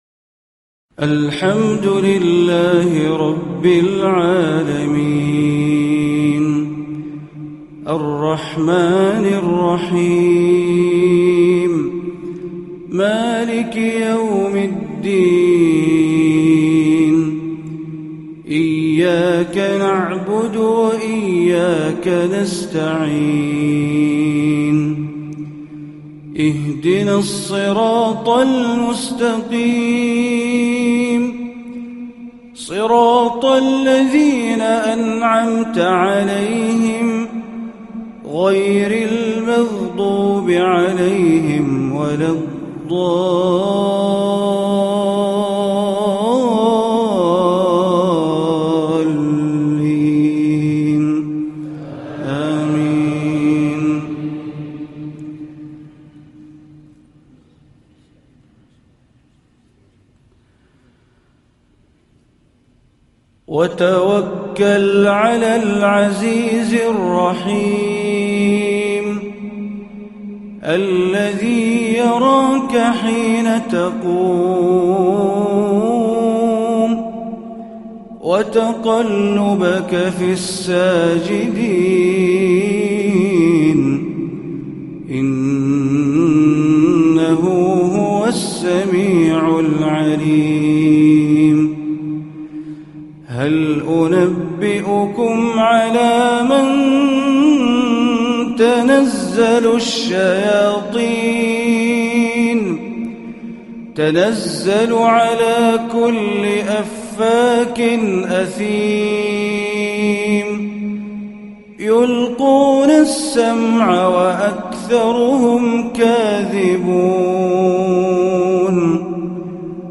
مغربية الشيخ بندر بليلة من جامع خادم الحرمين في الخبر ليوم الثلاثاء 7-5-1437هـ > تلاوات الشيخ بندر بليلة في المنطقة الشرقية عام 1437هـ > المزيد - تلاوات بندر بليلة